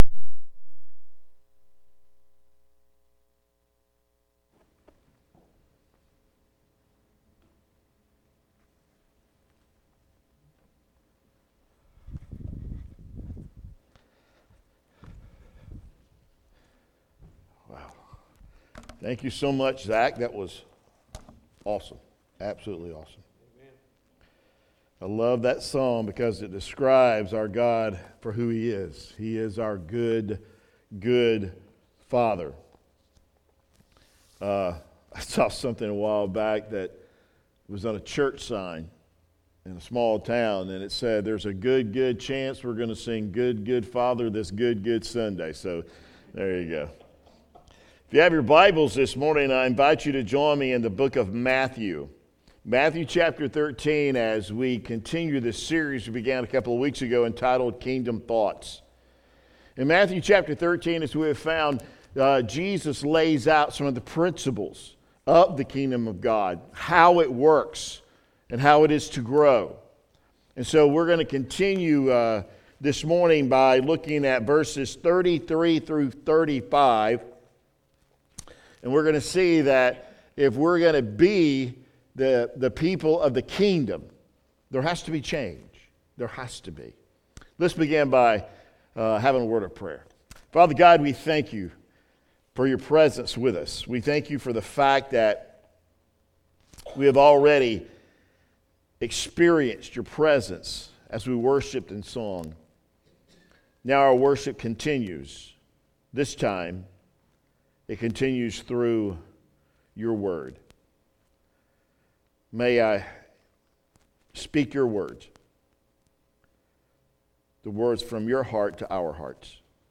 Lake Pointe Baptist Church Weekly Messages